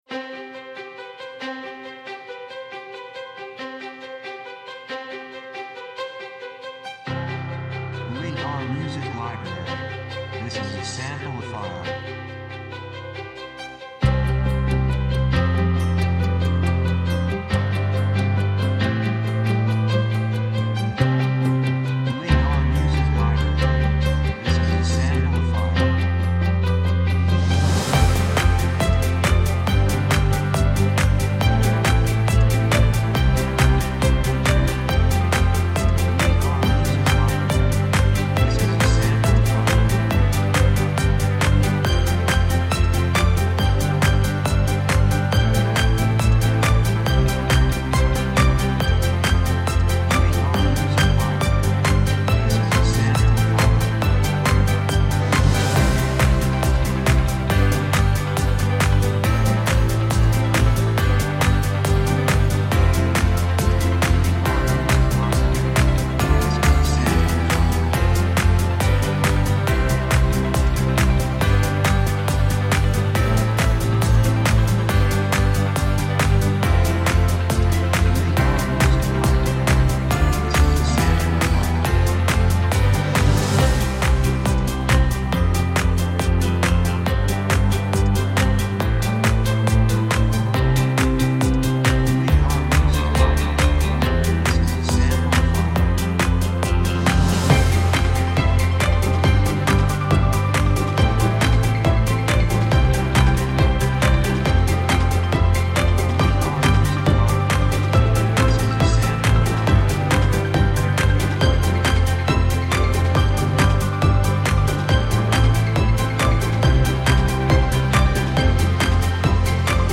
雰囲気幸せ, 高揚感, 決意, 喜び
曲調ポジティブ
楽器ピアノ, ストリングス, ボーカル, 手拍子
テンポ速い